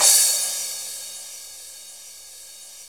Index of /90_sSampleCDs/Roland - Rhythm Section/KIT_Drum Kits 2/KIT_Soft Kit
CYM MAX C11L.wav